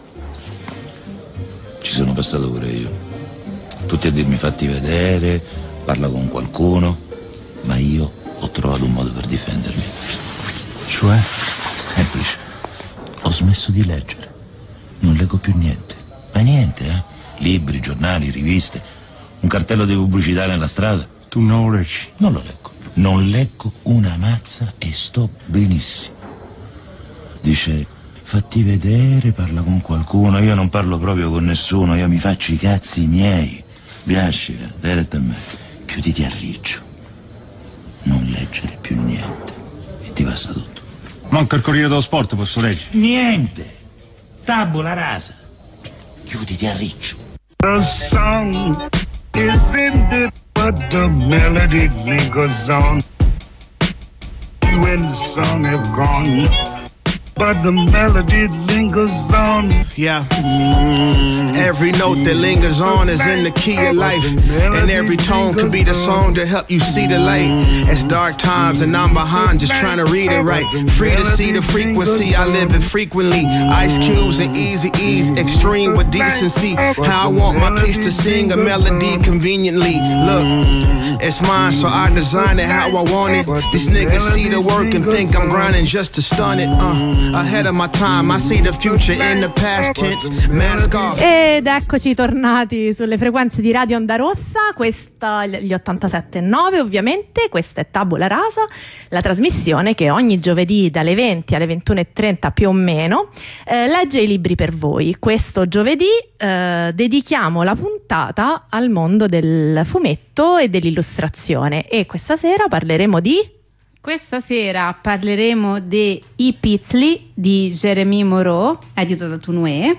La trasmissione che legge i libri per voi.